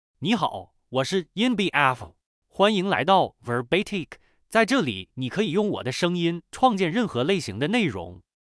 MaleChinese (Northeastern Mandarin, Simplified)
Yunbiao — Male Chinese AI voice
Yunbiao is a male AI voice for Chinese (Northeastern Mandarin, Simplified).
Voice sample
Listen to Yunbiao's male Chinese voice.
Male